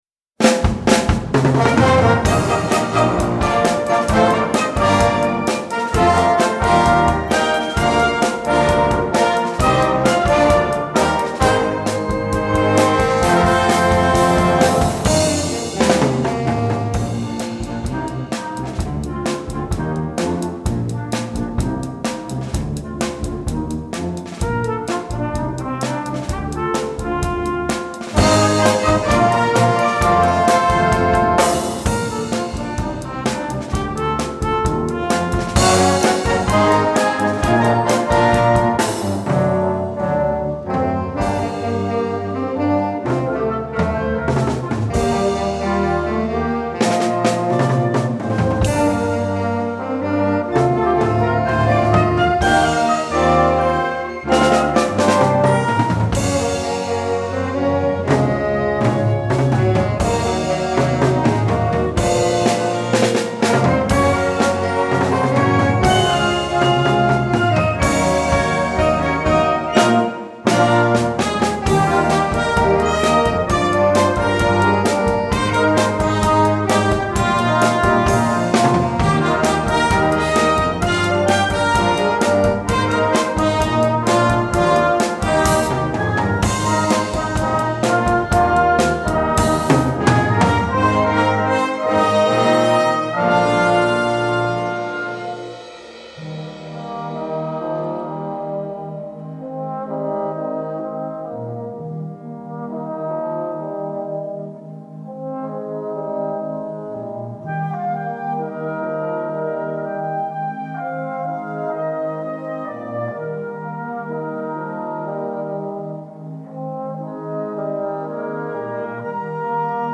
Partitions pour orchestre d'harmonie, ou fanfare.